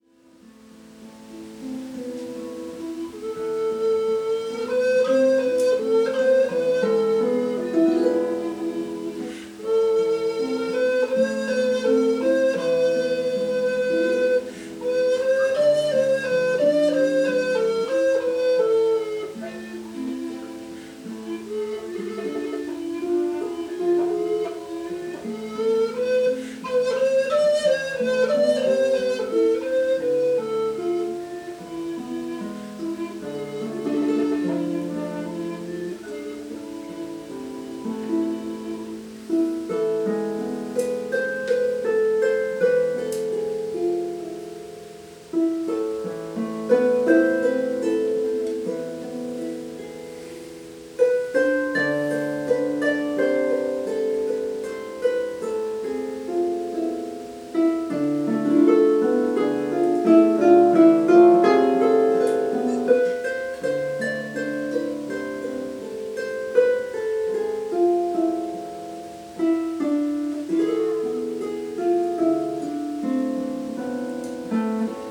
sephardisches traditionelles Liebeslied
In beiden Aufnahmen ist das Lied instrumental live zu hören: mit dem Harfenduo BARDENSANG UND ZAUBERKLANG bei einer Vernissage in Wiesloch 2017 und mit meiner Mittelaltertruppe CONVENTUS TANDARADEY beim Mittelaltermarkt »700 Jahre Spachbrücken« am Pfingstwochenende 2023.
BARDENSANG UND ZAUBERKLANG – Tenorblockflöte und Harfe Juni 20171:15 Min.
rosa_wiesloch.mp3